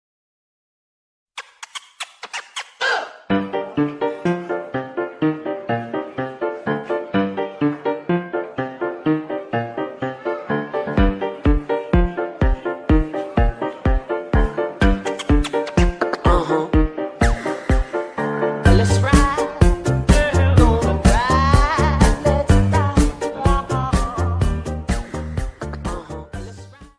Electro-Swing-Music-Dance_zYjhyRe-KyA.mp3